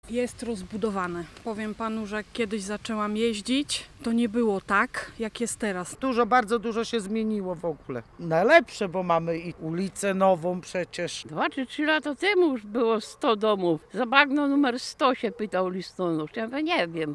Mieszkańcy Zabagna uważają, że miejscowość jest bardzo rozbudowana, a na przestrzeni lat dużo się w niej zmieniło.
Zabagno-Solectwo-Rozbudowa-mieszkancy.mp3